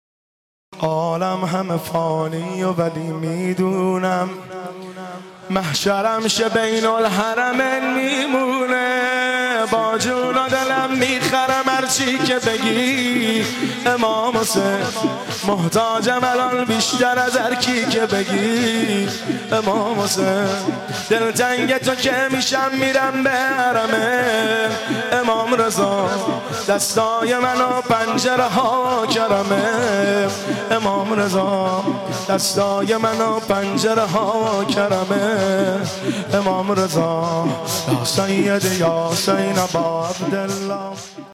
پادکست : تک آهنگ
دسته : پاپ